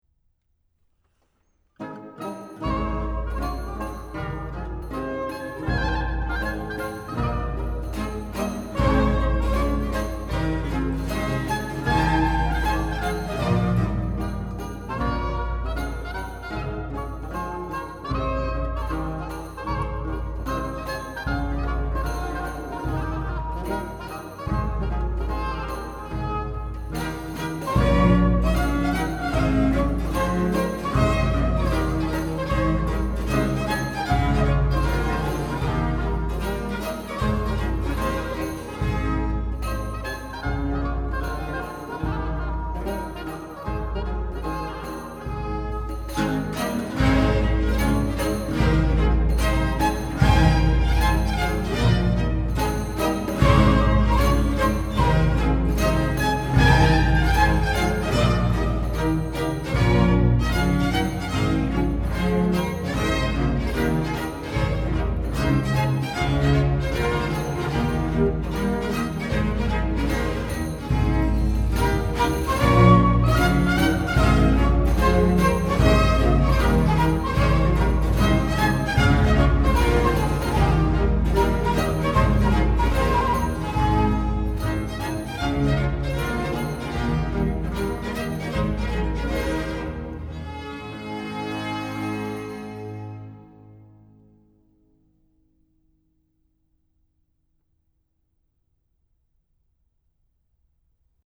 Gavotte